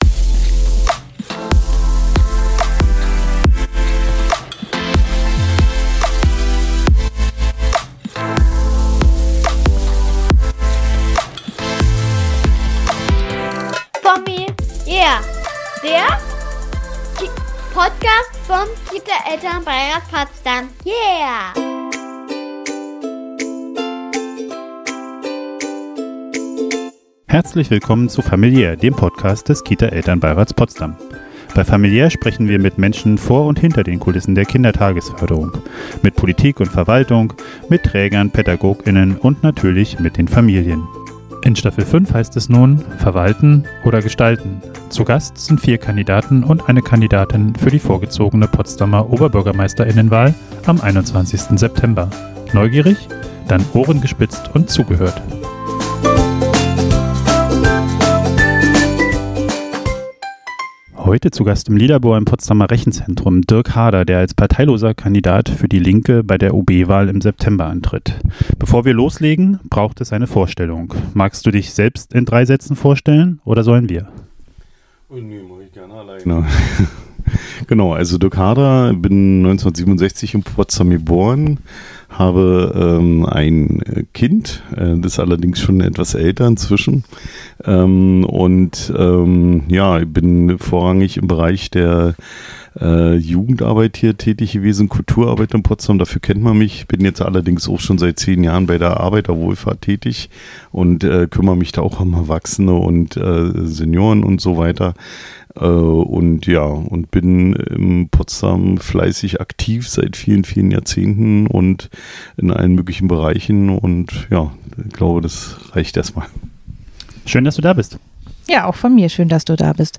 Zur fünften Staffel begrüßen wir vier Kandidaten und eine Kandidatin zur vorgezogenen Potsdamer Oberbürgermeister*innenwahl im Lilabor im Rechenzentrum.
Freut euch auf über zwei Stunden Austausch – natürlich über Familien- und Kita-Themen, aber auch darüber hinaus, aufgeteilt in fünf badewannentaugliche Interviews.